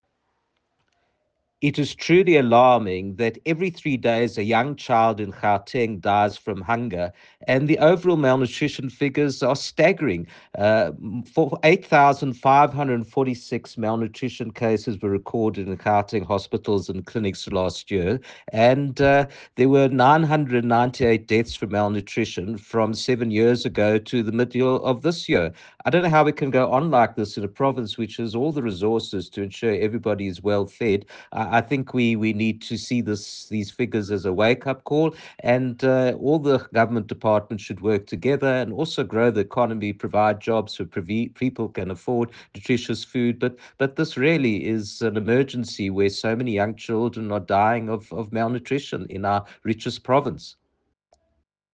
soundbite by Dr Jack Bloom MPL.